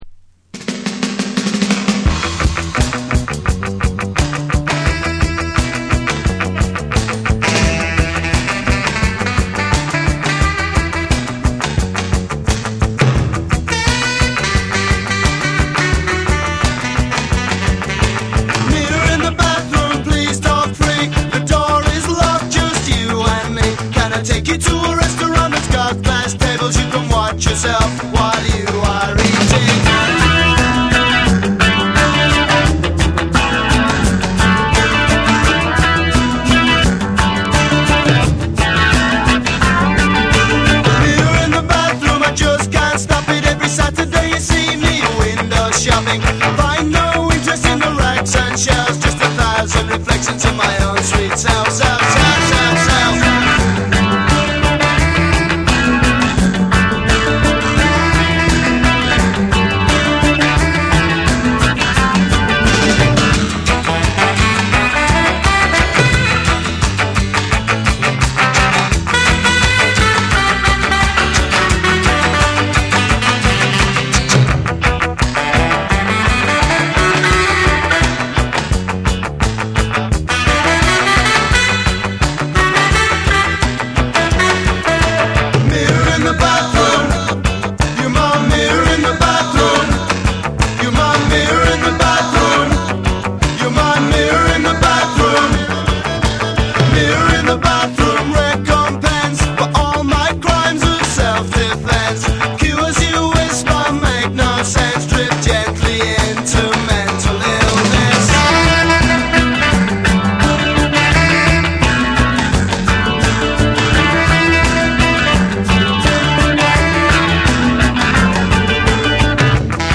ロック、ダブ、ポストパンク、スカ、ヒップホップなどを横断したセレクションで、ダークでスモーキーな世界観を構築。
BREAKBEATS / NEW WAVE & ROCK / REGGAE & DUB